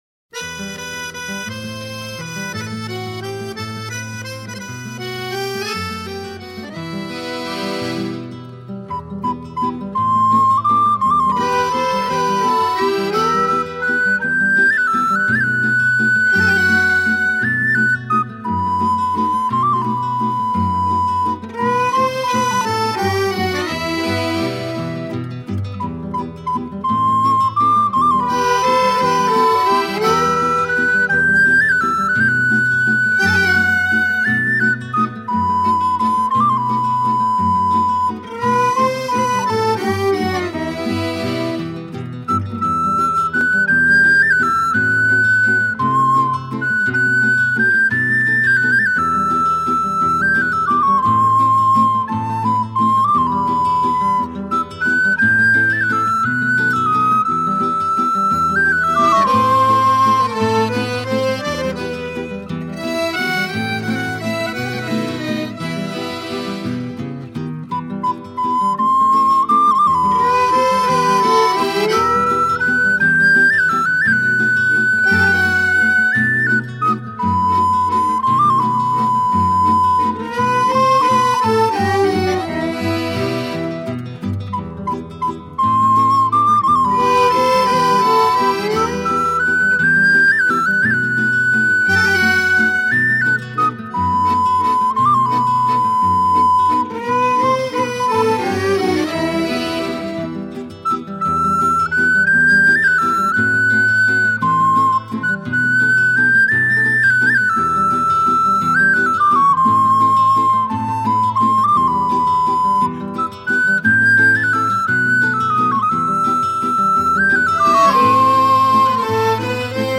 Canti Popolari Siciliani - Si Maritau Rosa (Midi).